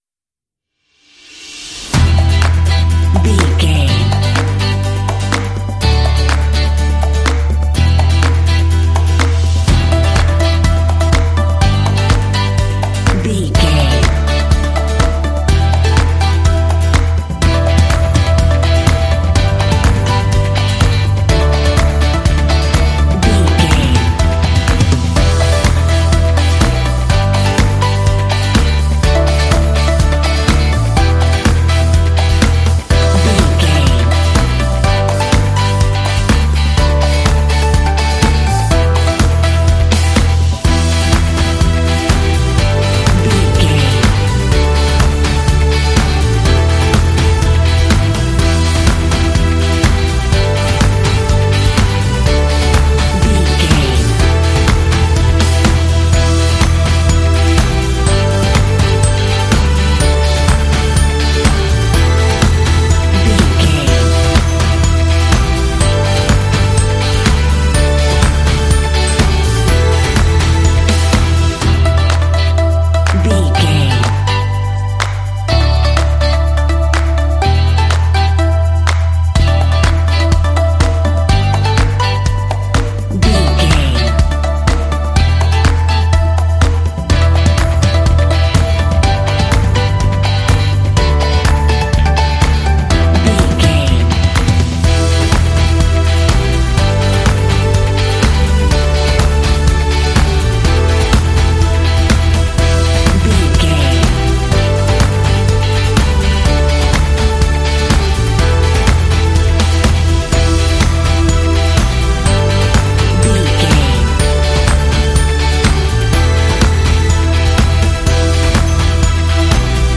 Epic / Action
Uplifting
Aeolian/Minor
lively
cheerful
acoustic guitar
electric guitar
bass guitar
strings
drums
percussion
synthesiser
synth-pop
rock
indie